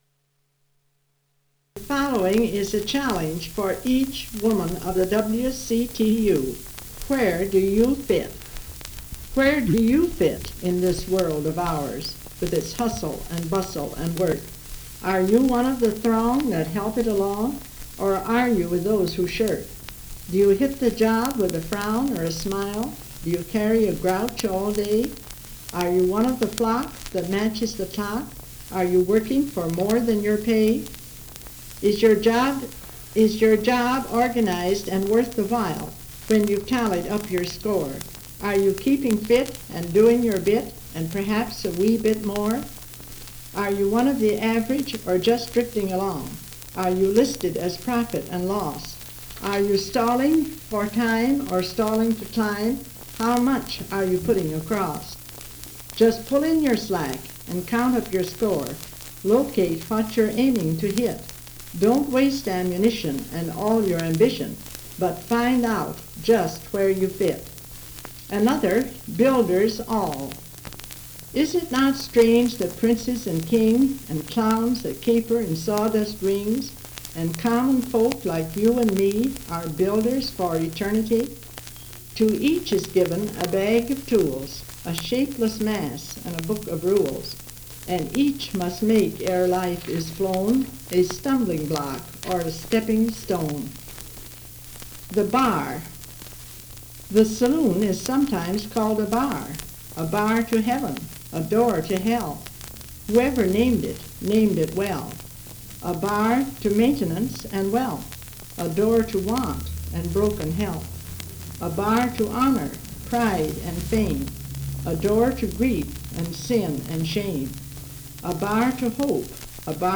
Sound quality impacted by hiss and pops
Container note: Recording discs made by Wilcox-Gay.
78 rpm; 8 inches and 1 user audio file